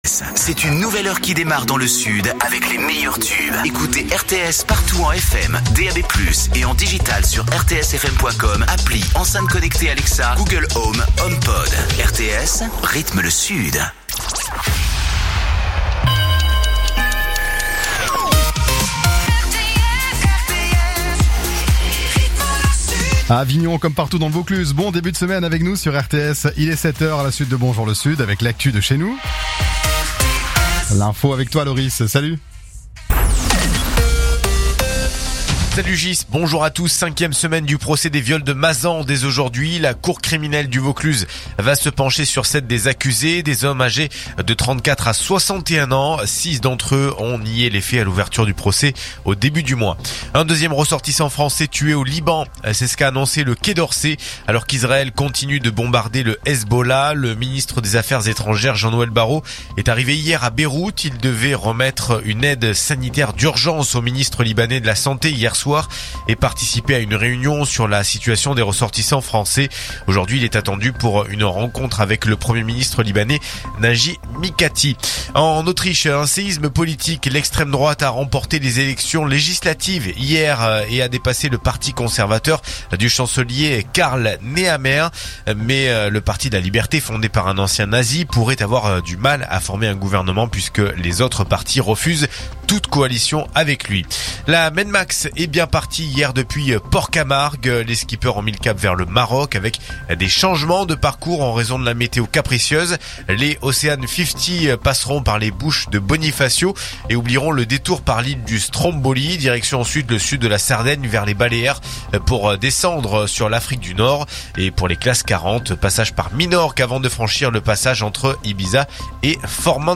Écoutez les dernières actus d'Avignon en 3 min : faits divers, économie, politique, sport, météo. 7h,7h30,8h,8h30,9h,17h,18h,19h.